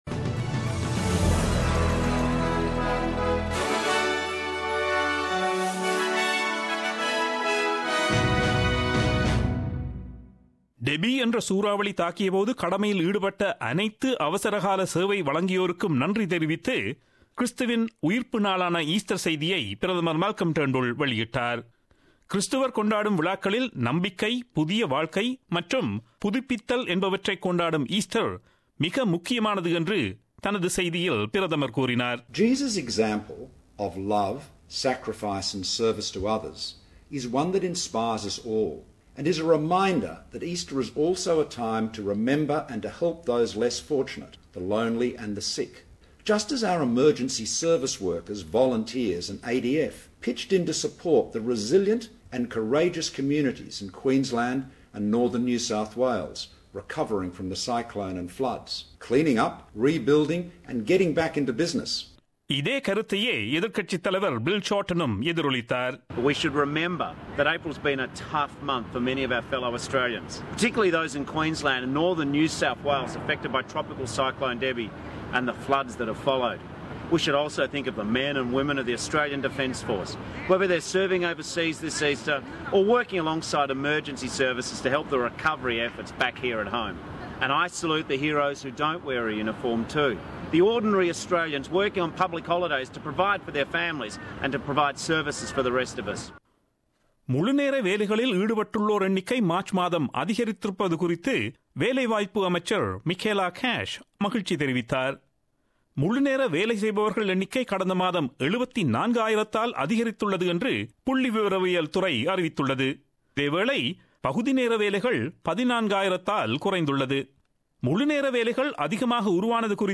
Australian news bulletin aired on Friday 14 Apr 2017 at 8pm.